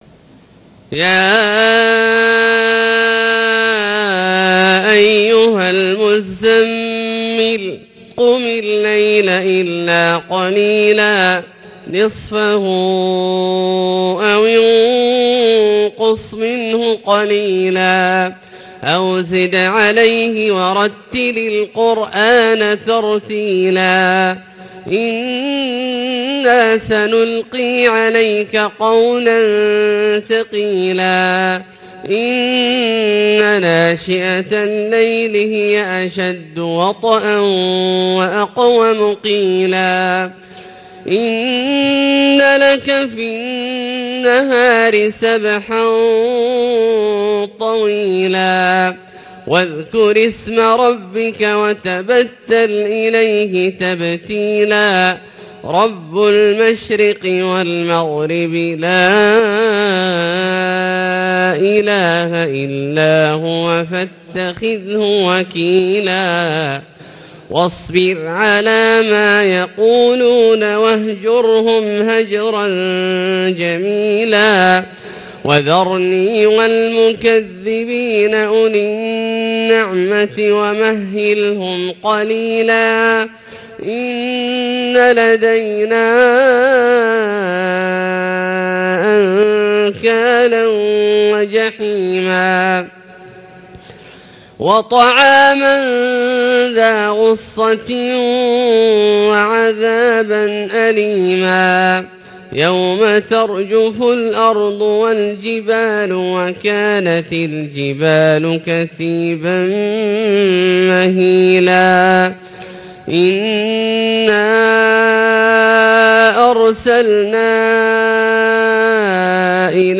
Fajr prayer of surat almuzzamil > 1439 H > Prayers - Abdullah Al-Juhani Recitations